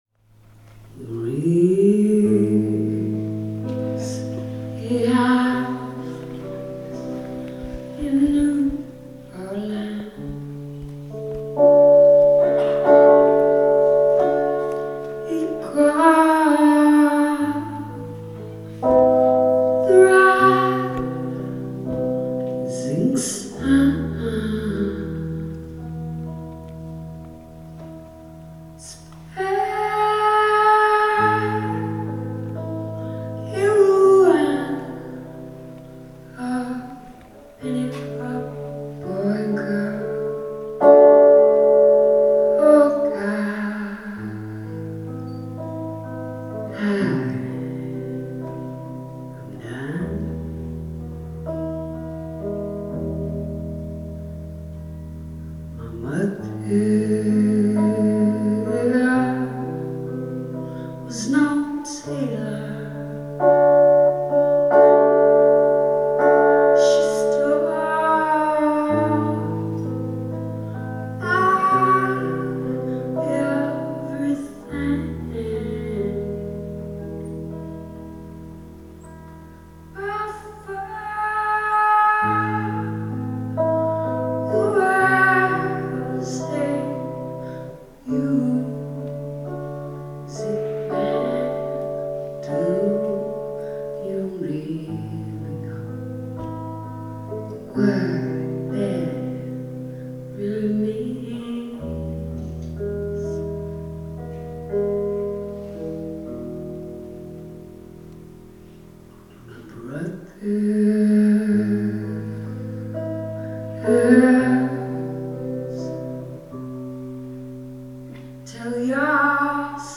Live at the Museum of Fine Arts Boston